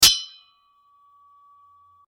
Clash.mp3